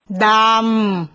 seé dahm